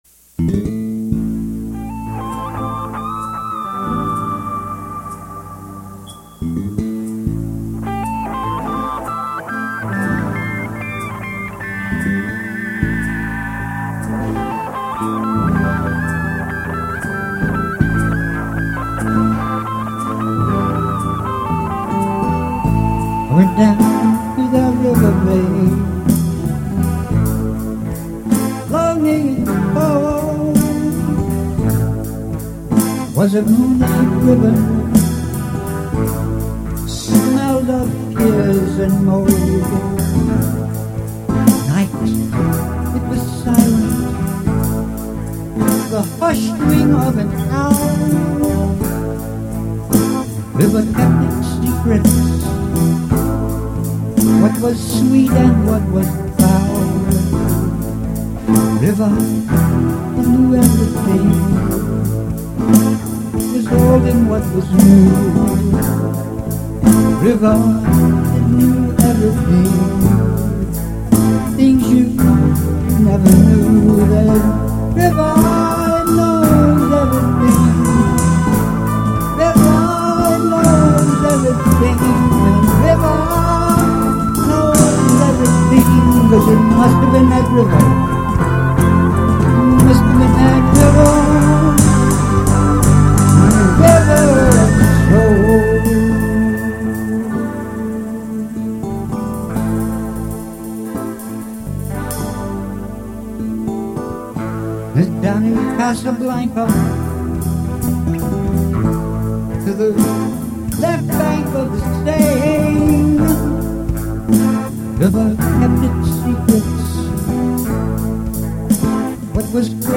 some of the guitar reminds me of a moody blues kind of twang
some of it is late 60s-ish blues rock
Songs played and sung in minor keys are, indeed, haunting.